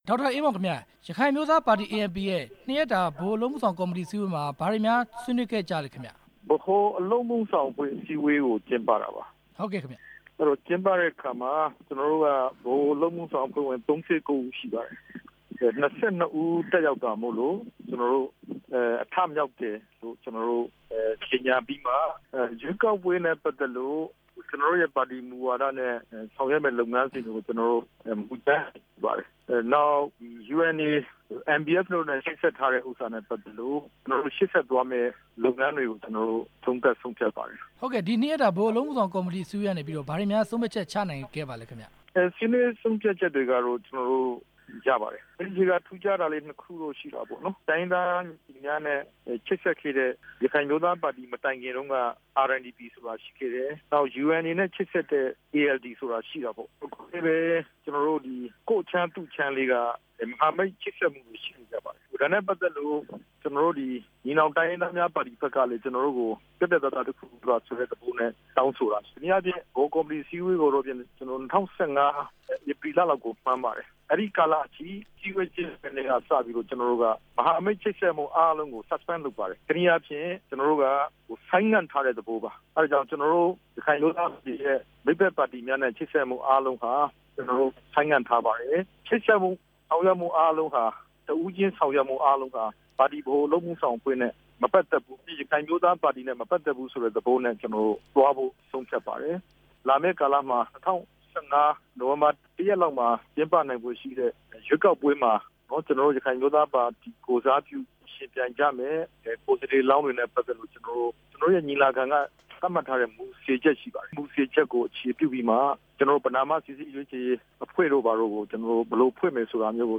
၂ ရက်ကြာ ကျင်းပခဲ့တဲ့ ရခိုင်အမျိုးသားပါတီရဲ့ အစည်းအဝေးဆုံးဖြတ်ချက်တွေအကြောင်း မေးမြန်းချက်